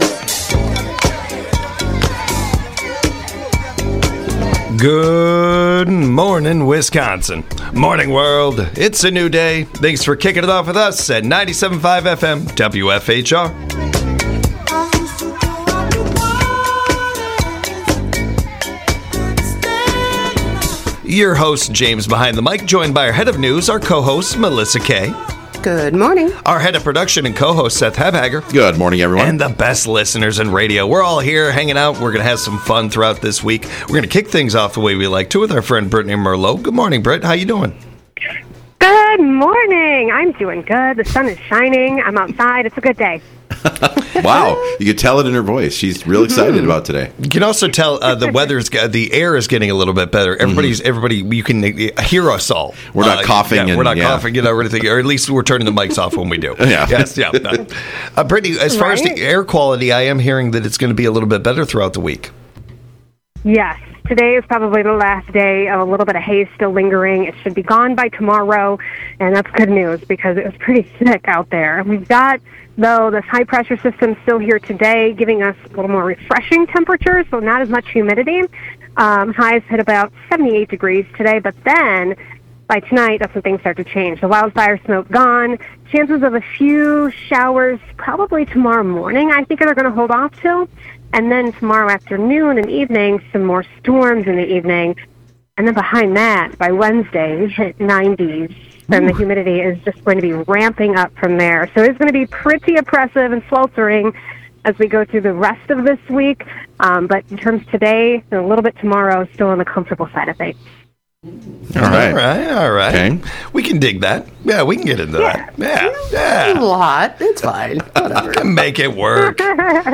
Welcome to this Monday Mornings with WFHR!